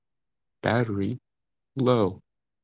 battery-low.wav